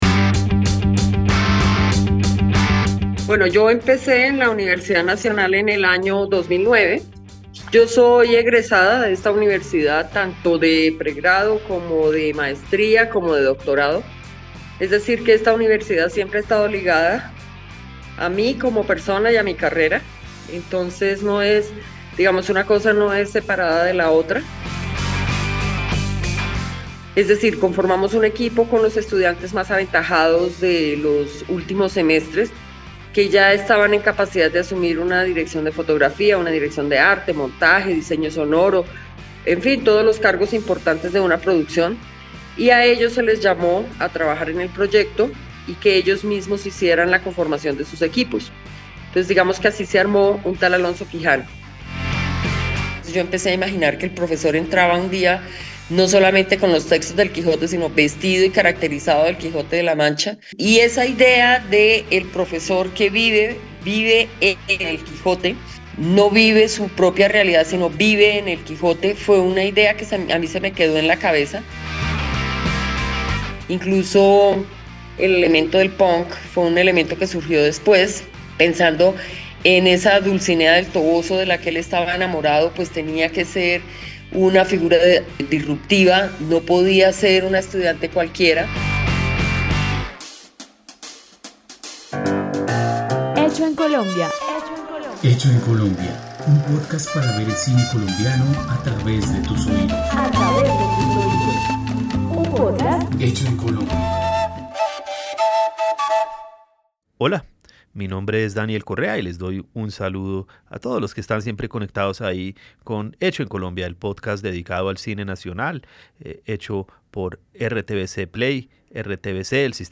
Una conversación profunda